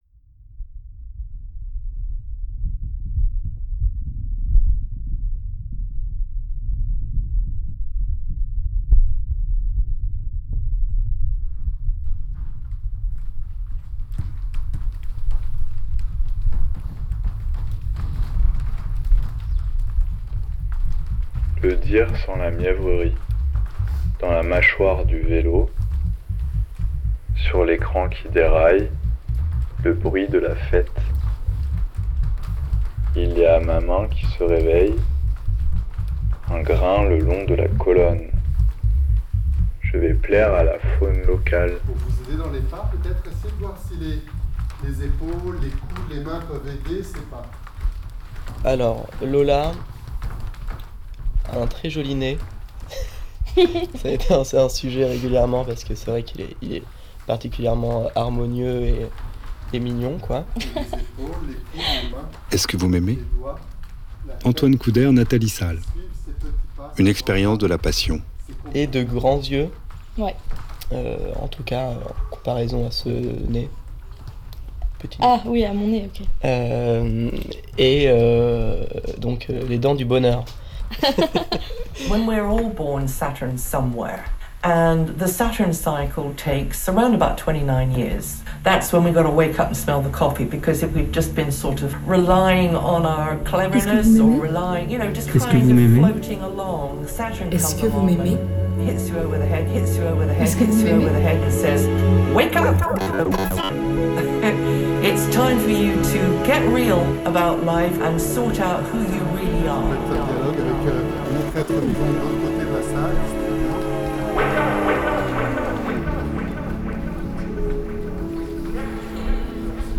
Une création immersive à écouter, de préférence, au casque.